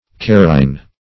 Search Result for " kairine" : The Collaborative International Dictionary of English v.0.48: Kairine \Kai"rine\, n. (Chem.)